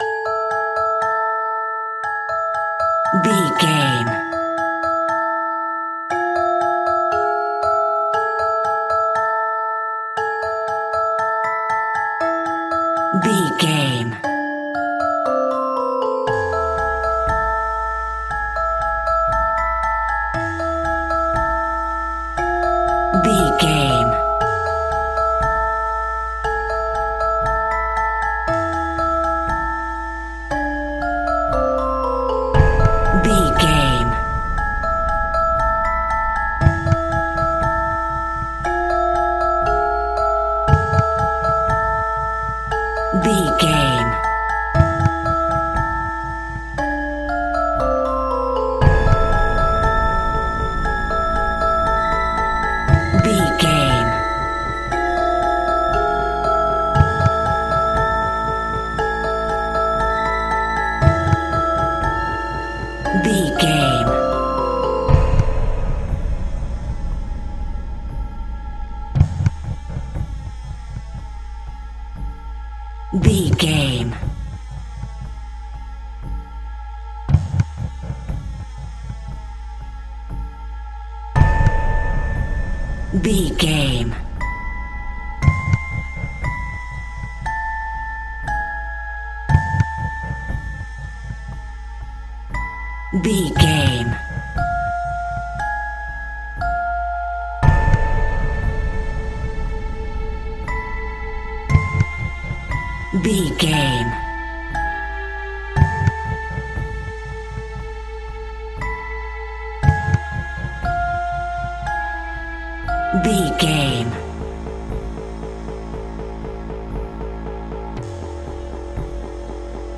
Aeolian/Minor
scary
ominous
suspense
eerie
piano
electric piano
synthesiser
strings
drums
horror